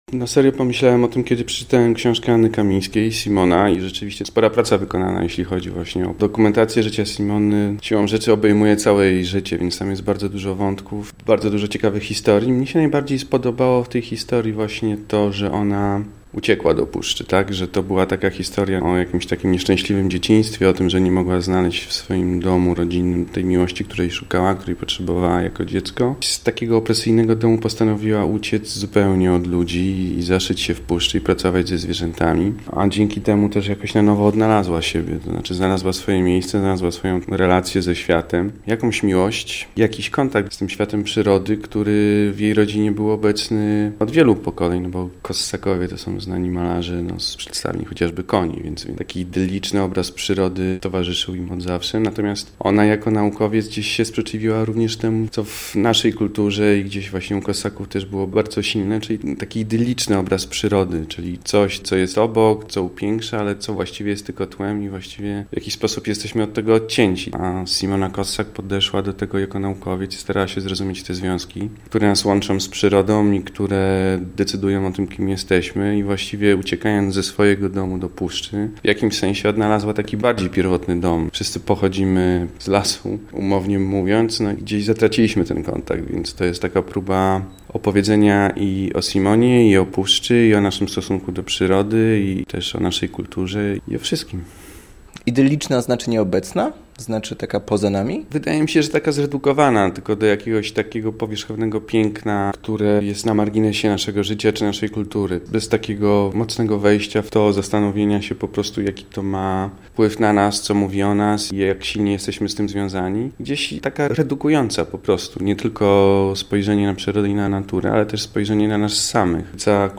Z reżyserem rozmawiamy o relacji człowieka i natury oraz o specyfice pracy ze zwierzęcymi aktorami. Adrian Panek mówi o tym, skąd inspiracja na zajęcie się tym tematem.